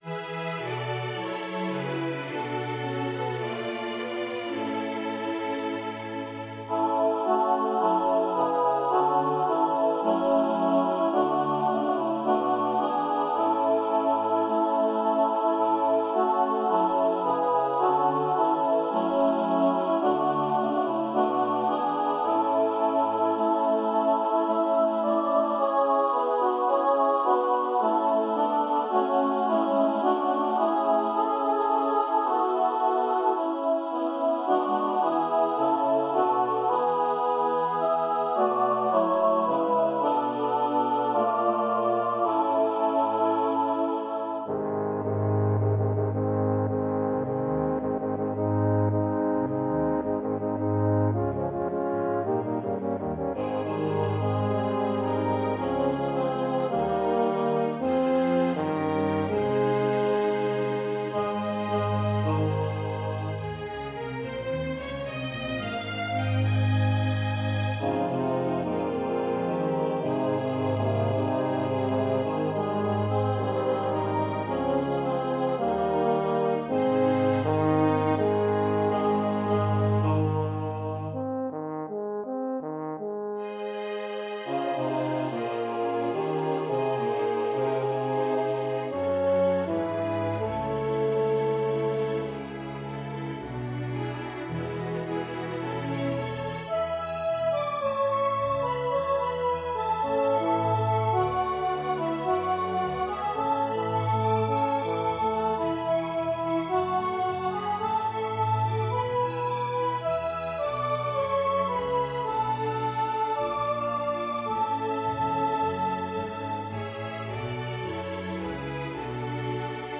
The current version is for soloists, SATB choir, string section and French horn section.